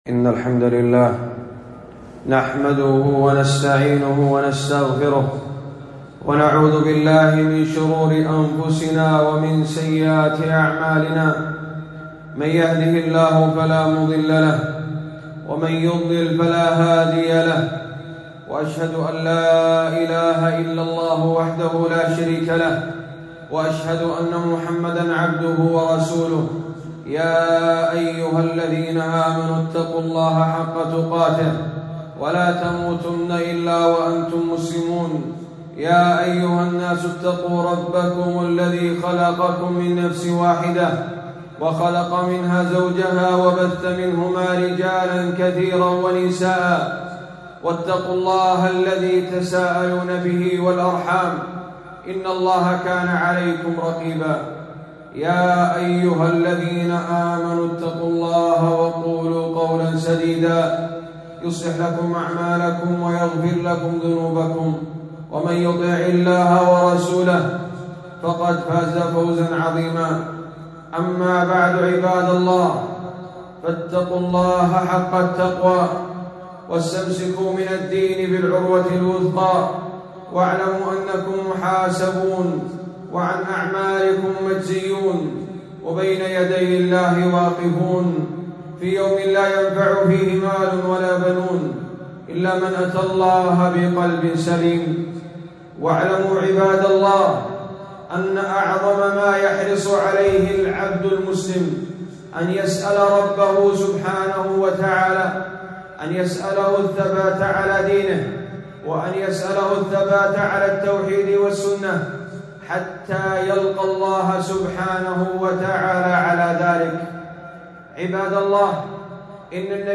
خطبة - حرمة الاستشفاع بالله على الخلق